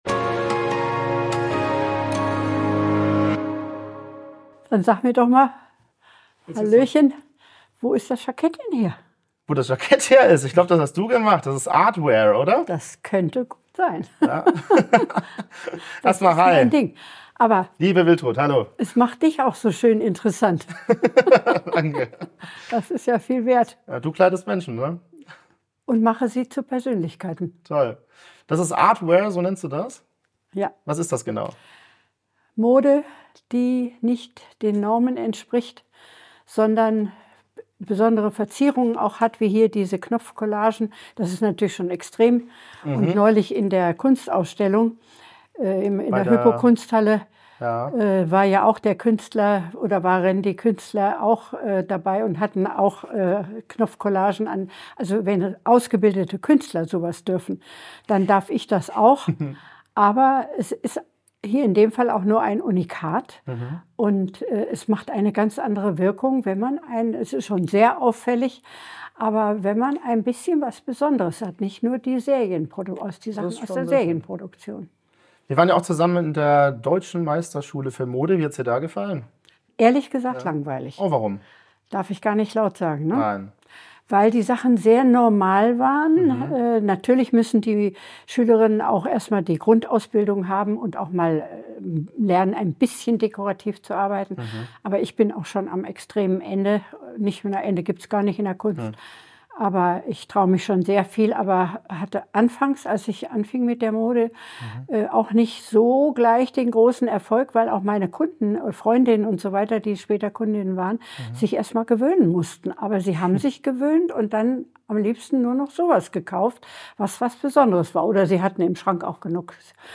Zusammenfassung In dem Gespräch geht es um die einzigartigen Modedesigns der Interviewten, die sie als eine Form von Kunstkleidung betrachtet, die über die traditionellen Modestandards hinausgeht.